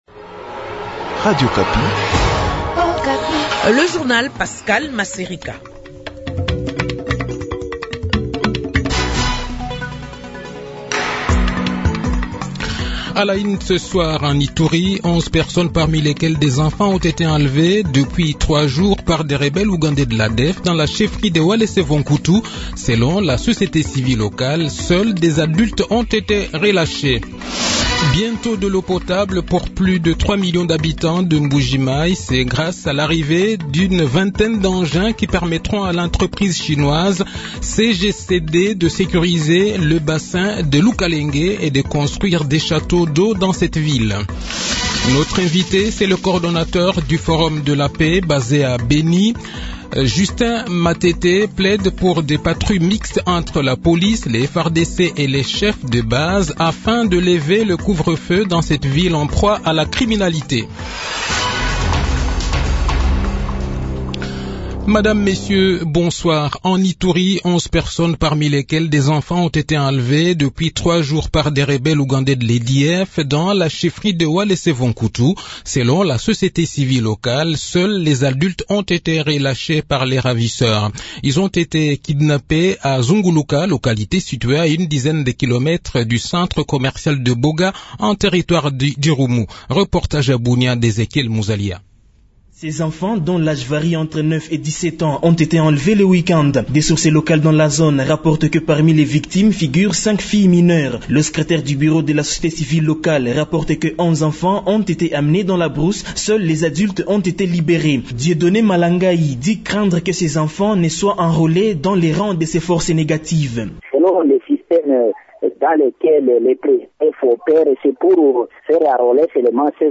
Le journal de 18 h, 31 Aout 2021